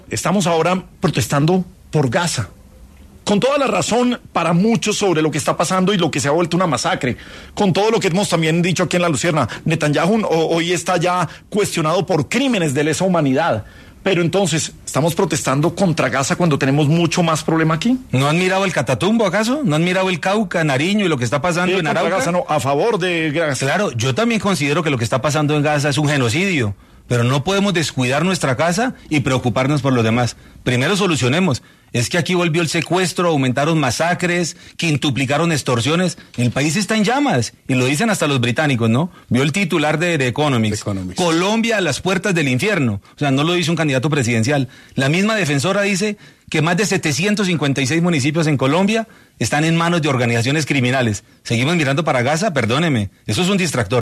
El exgobernador Juan Guillermo Zuluaga habló en Sin Anestesia de La Luciérnaga sobre lo que está pasando en Gaza y en Colombia